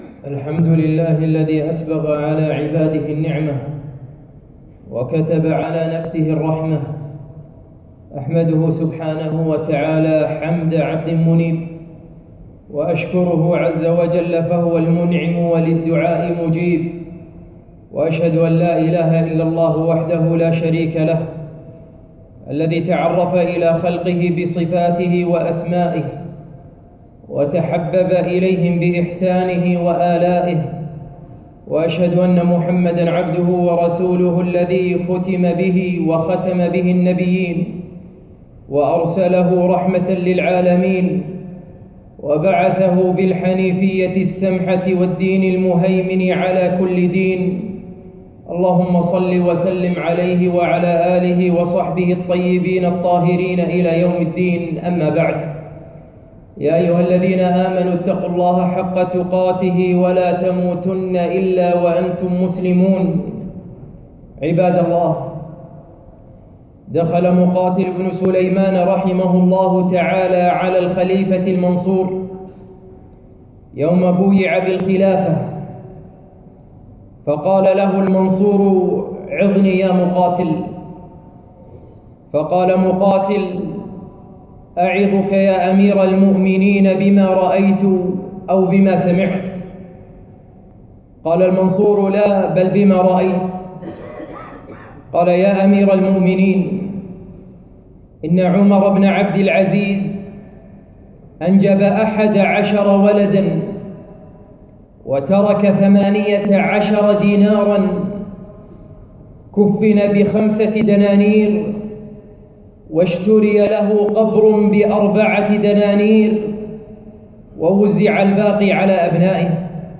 خطبة - فتنة المال